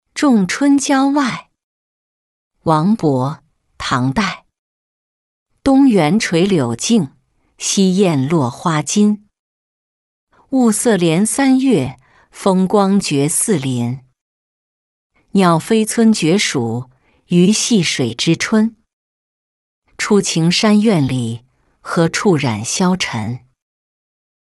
仲春郊外-音频朗读